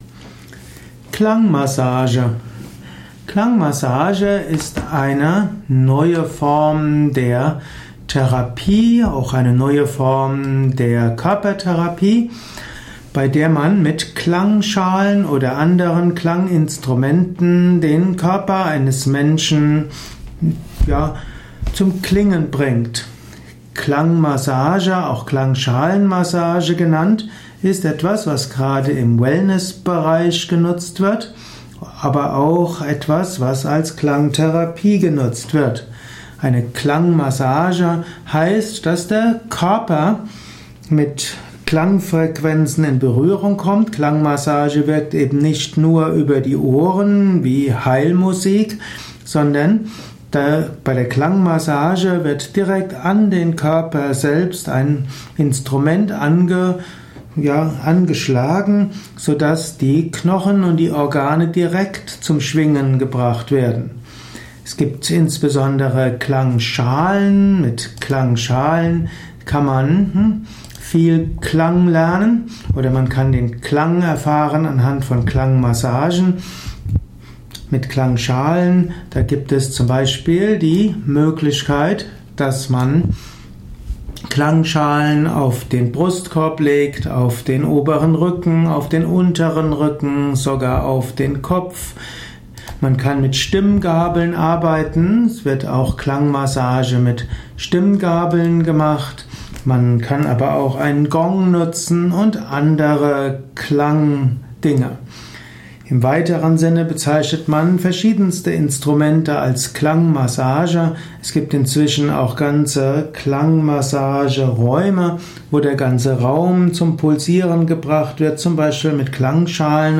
Dieser Vortrag ist ursprünglich konzipiert